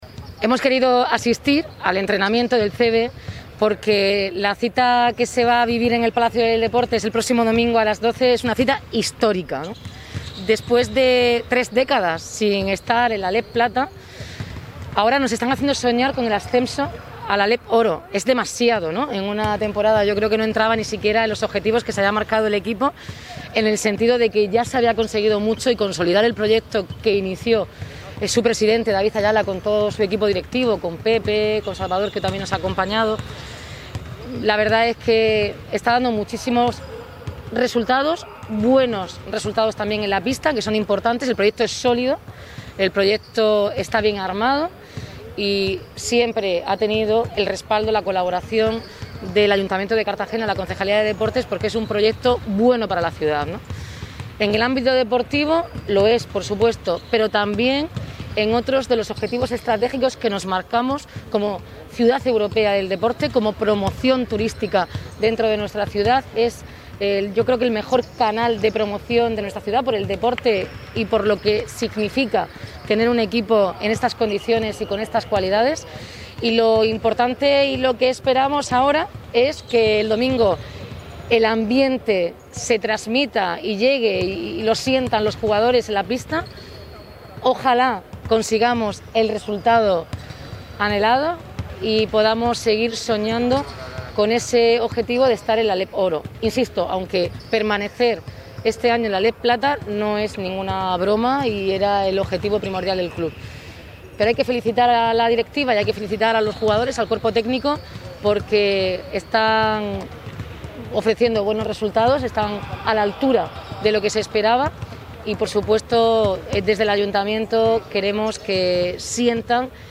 Enlace a Declaraciones Noelia Arroyo